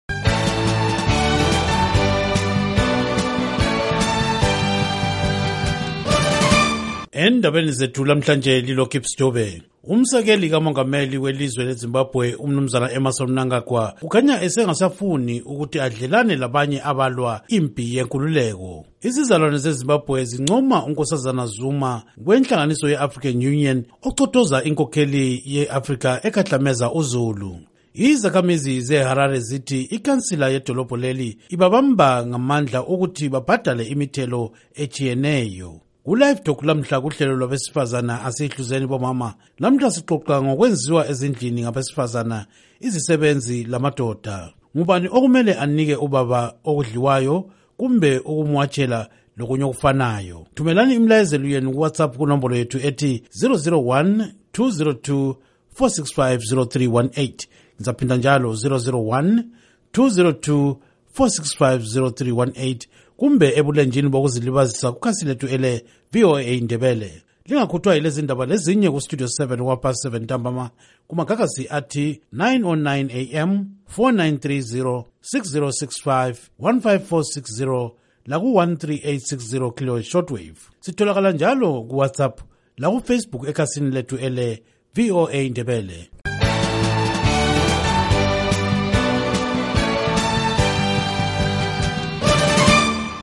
Inhloko zeNdaba